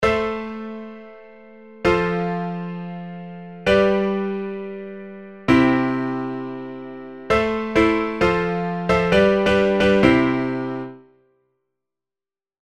たとえばCFGCの最初のCを、同じTであるAmに変えてみます。（Am⇒F⇒G⇒C）
なんか、有名な”あのイントロ”が聞こえてきそうな感じですね。